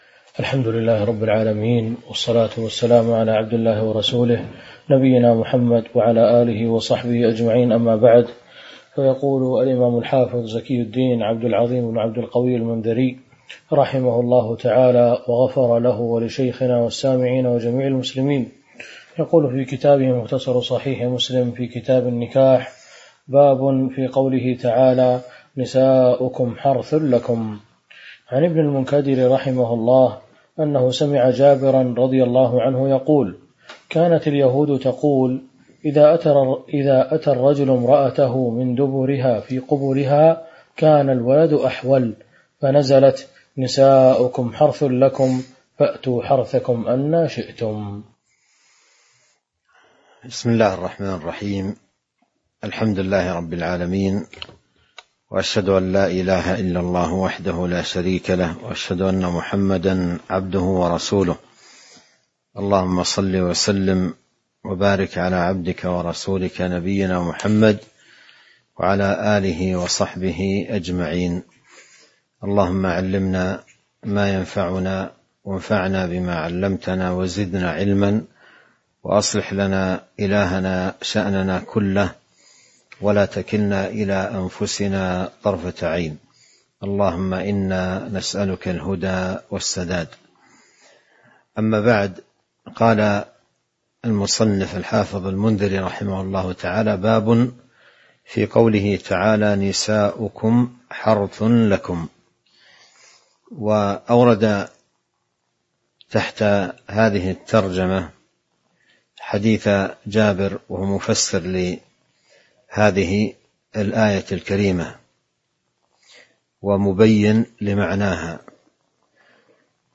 تاريخ النشر ٥ محرم ١٤٤٣ هـ المكان: المسجد النبوي الشيخ